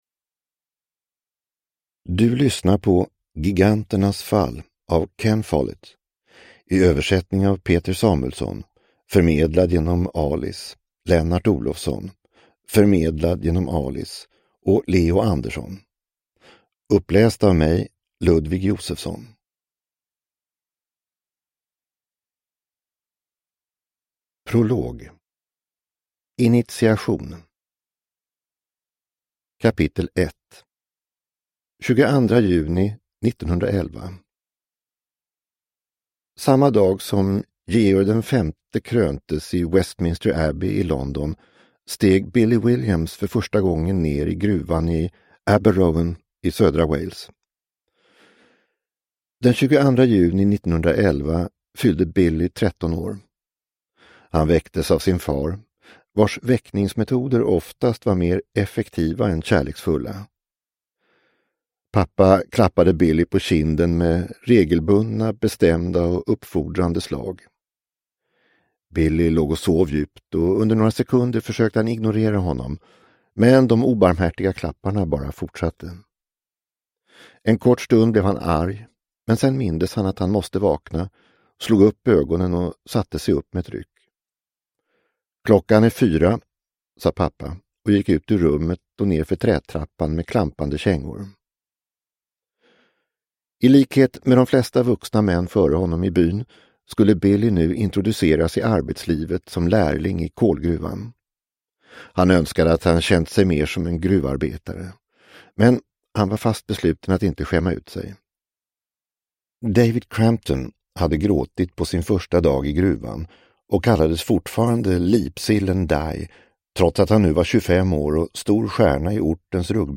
Giganternas fall – Ljudbok – Laddas ner